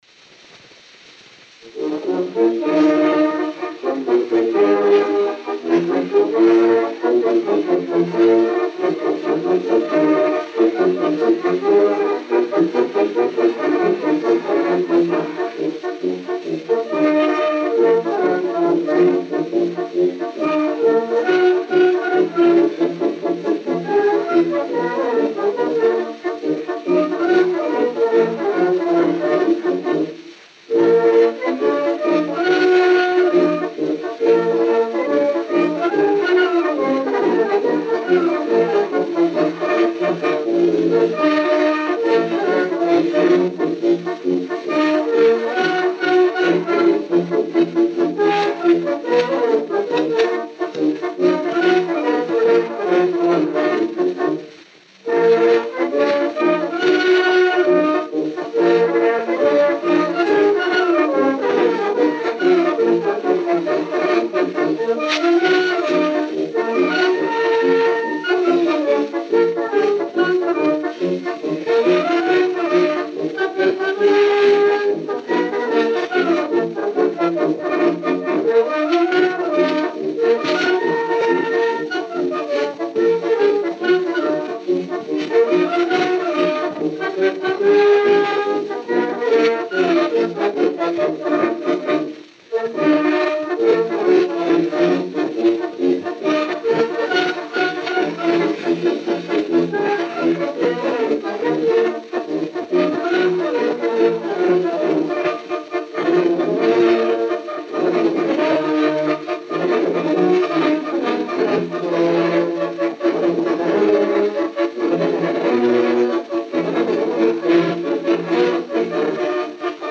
Gênero: Dobrado.